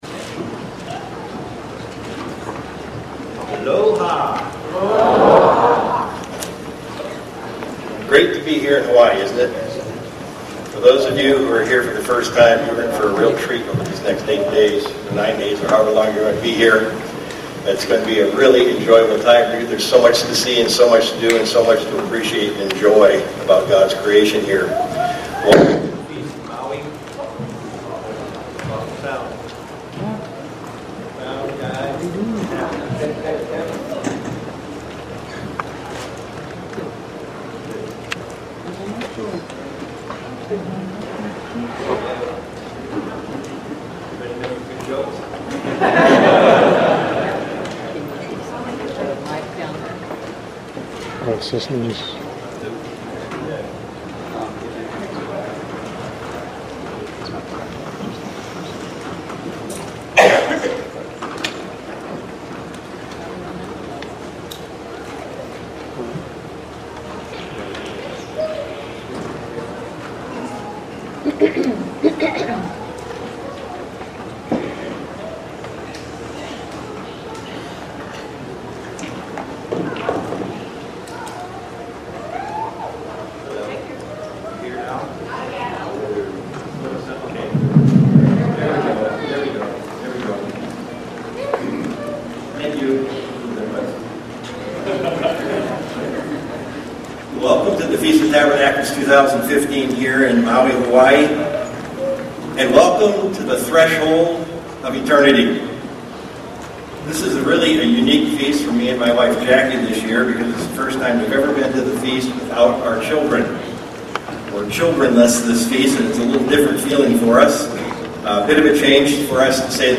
This sermon was given at the Maui, Hawaii 2015 Feast site.